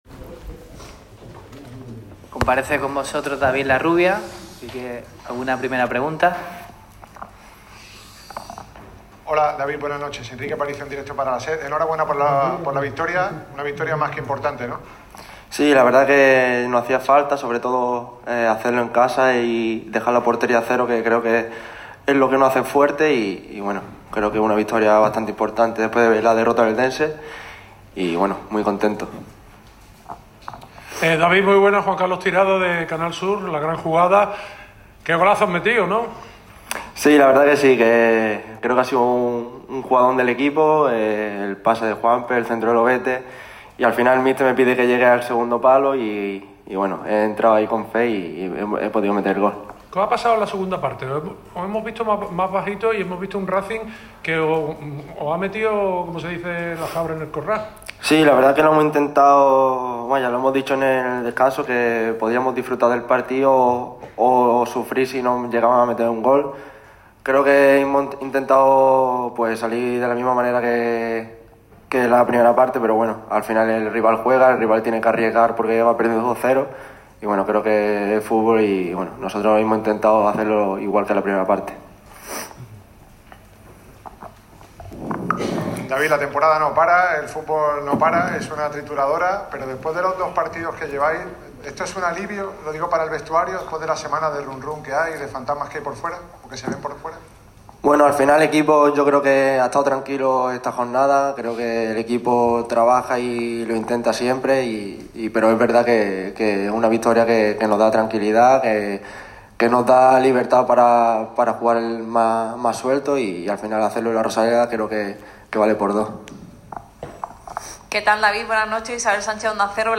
El mediapunta de La Luz ha comparecido ante los medios de comunicación al término del encuentro que ha enfrentado al Málaga CF contra el Racing de Ferrol (2-0).